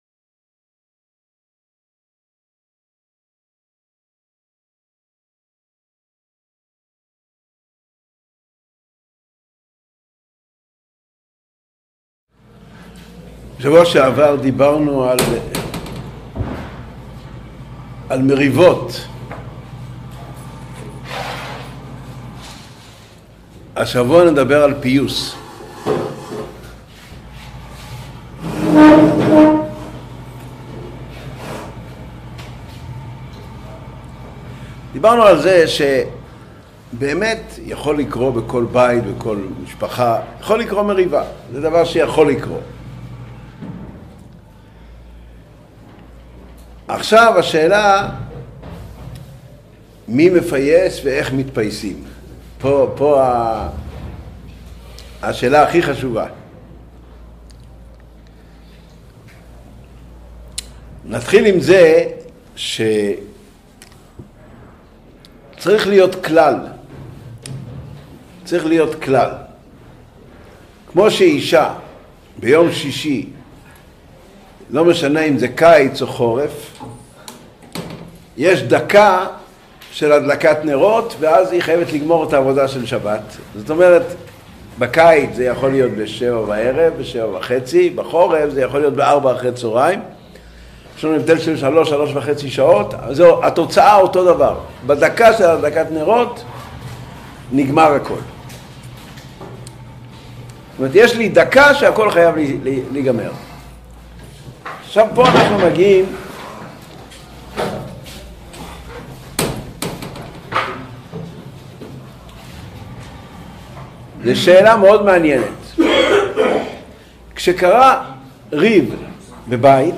Урок № 11. Примирение. Содержание урока: Кто осуществляет примирение и каким образом?